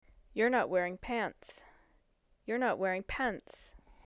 The two productions of pants were spoken by a female native speaker of American English who was wearing pajama pants at the time of the recording.
c. The funny pants. In my head at least, the second pants version is way funnier than the first. Especially when used in a sentence, such as “you’re not wearing pants:”